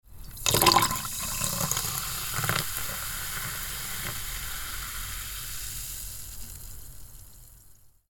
Pouring Fizzy Soda Into Glass Sound Effect
High-quality sound effect of a carbonated fizzy drink being poured into a glass. Features crisp bubbles, intense carbonation hiss, and the satisfying splash of liquid filling up.
Pouring-fizzy-soda-into-glass-sound-effect.mp3